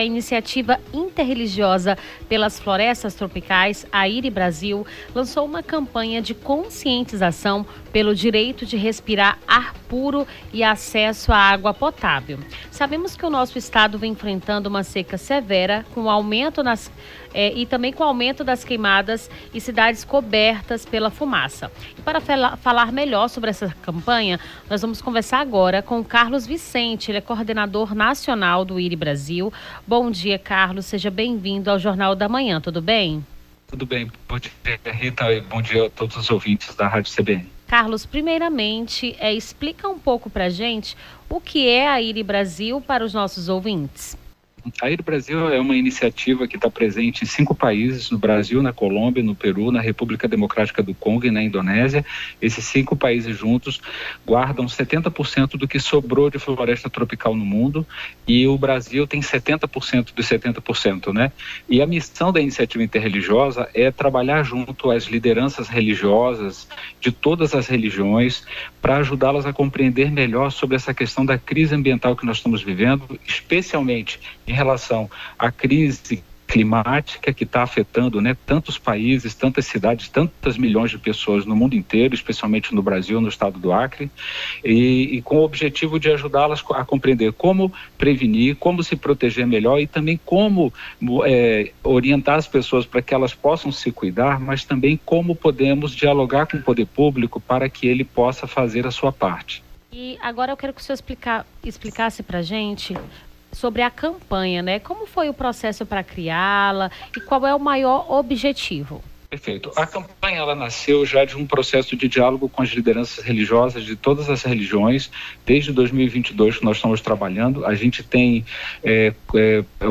Nome do Artista - CENSURA - ENTREVISTA CAMPANHA AR PURO SECA (09-09-24).mp3